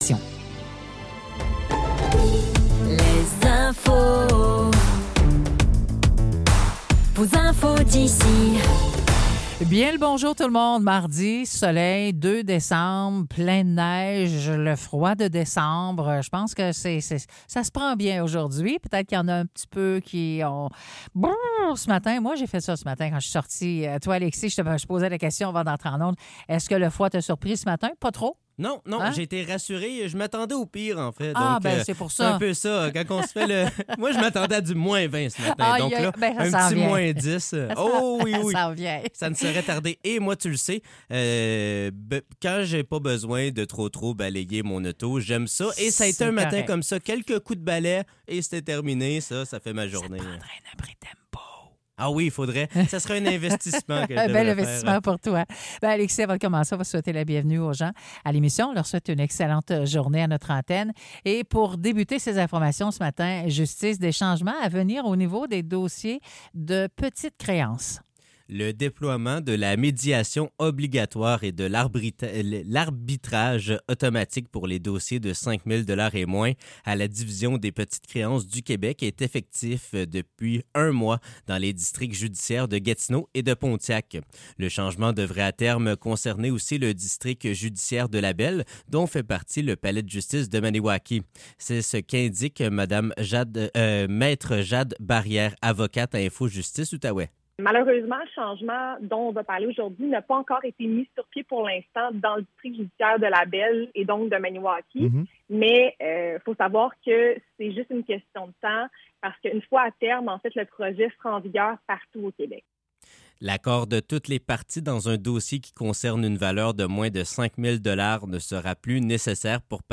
Nouvelles locales - 2 décembre 2025 - 9 h